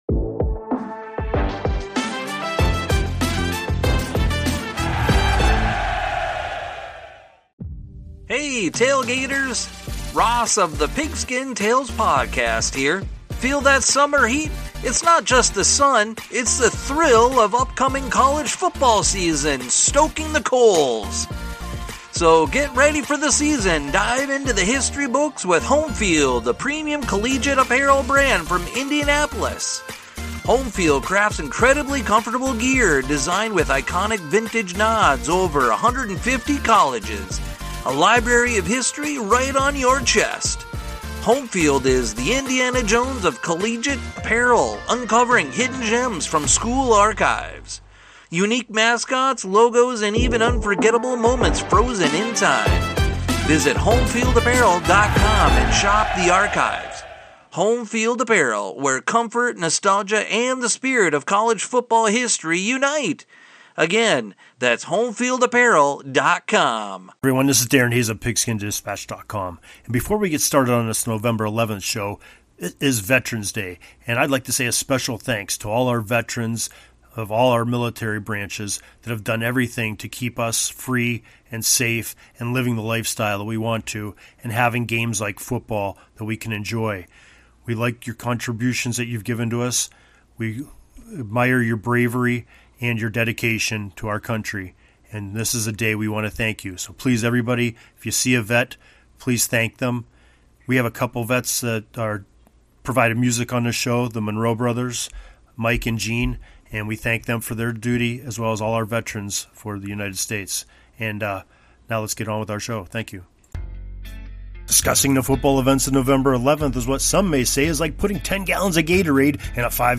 We also feature great music